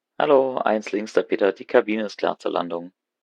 CallCabinSecureLanding.ogg